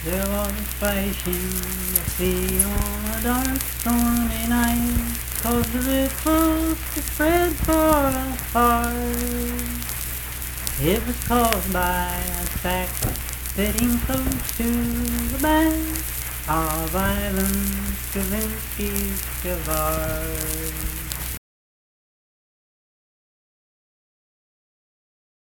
Unaccompanied vocal music
Verse-refrain 1(4).
Performed in Frametown, Braxton County, WV.
Voice (sung)